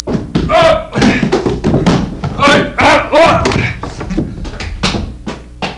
Man Falling Sound Effect
Download a high-quality man falling sound effect.
man-falling.mp3